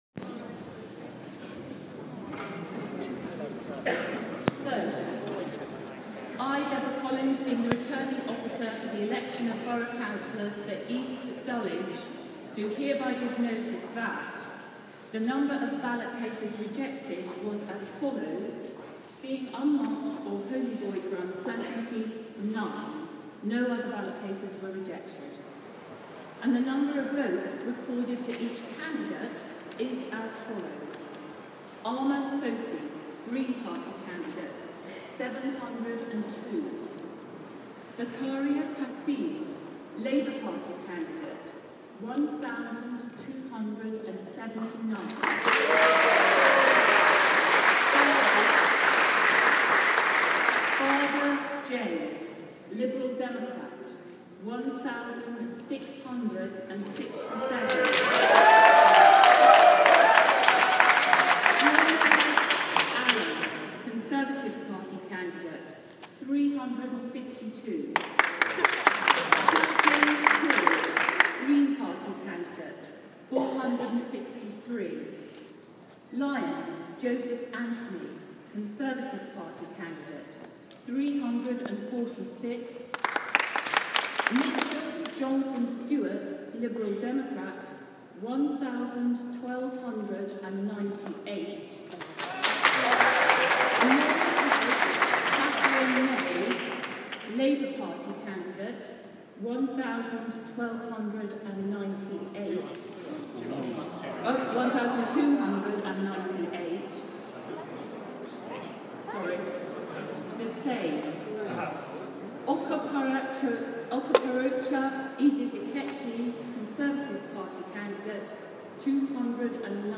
East Dulwich ward declaration